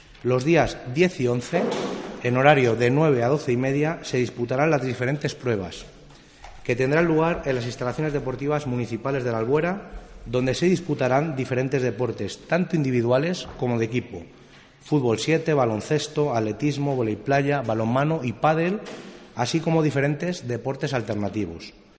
Jesús Garrido es el concejal de Deportes.